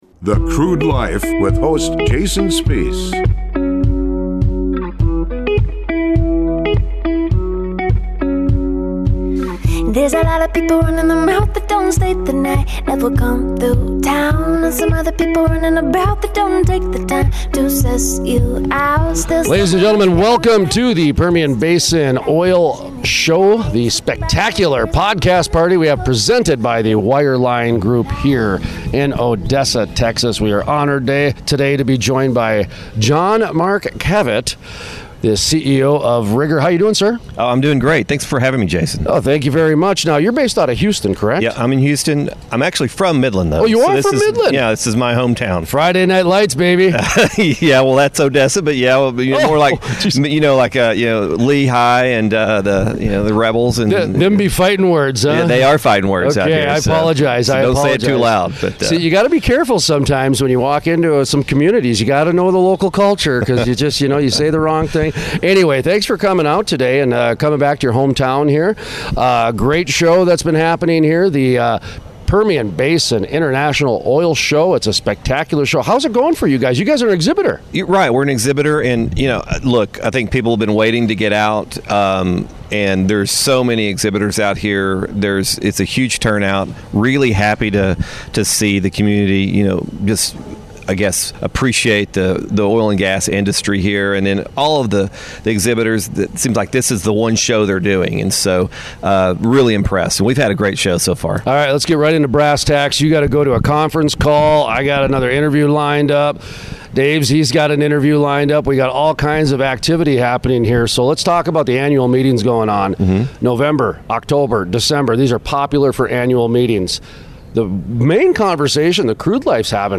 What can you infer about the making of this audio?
at the PBIOS Podcast Party sponsored by The Wireline Group at the Permian Basin International Oil Show (PBIOS) in Odessa, Texas.